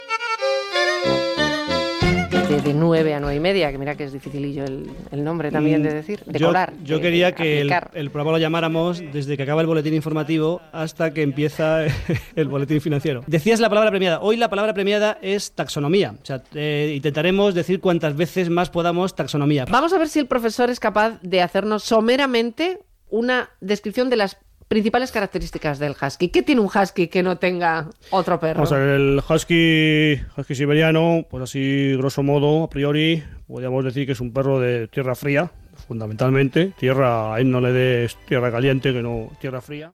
Diàleg dels presentadors i apunt sobre el gos Husky siberià
Entreteniment